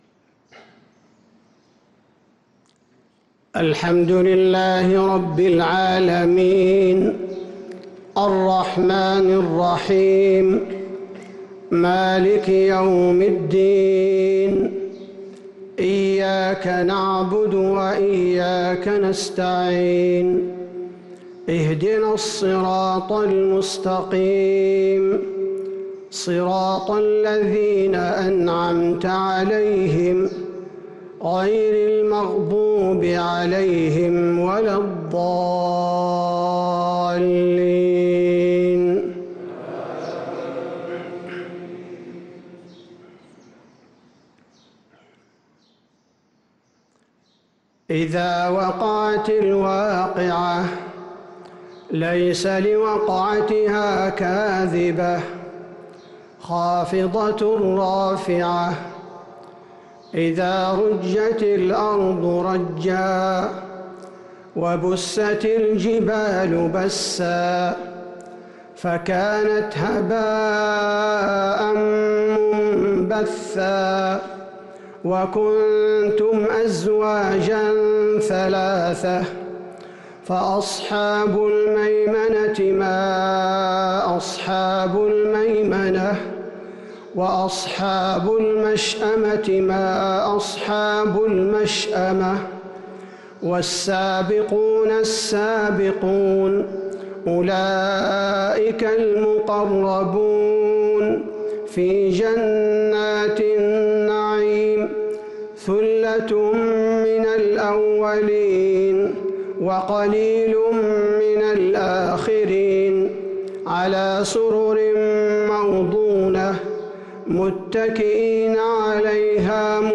صلاة الفجر للقارئ عبدالباري الثبيتي 30 رمضان 1443 هـ
تِلَاوَات الْحَرَمَيْن .